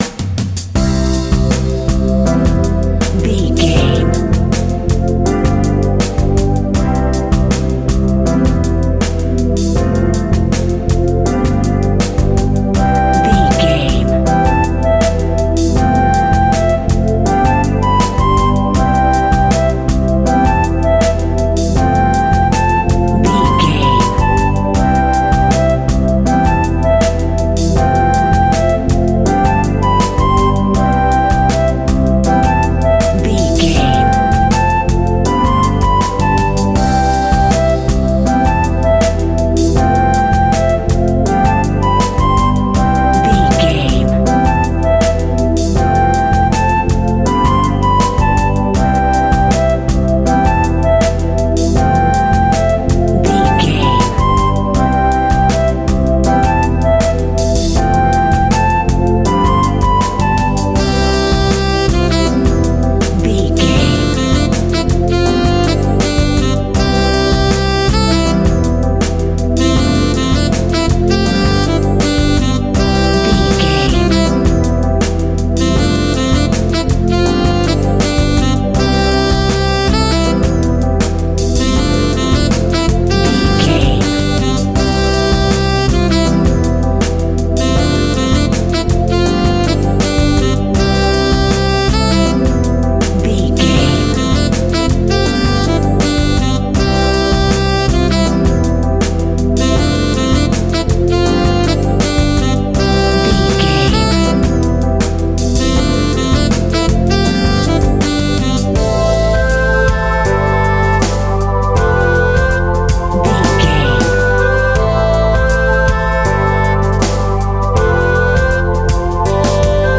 Kids Music
Uplifting
Ionian/Major
Slow
instrumentals
fun
childlike
cute
happy
kids piano